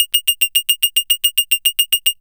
70s Random 109-F#.wav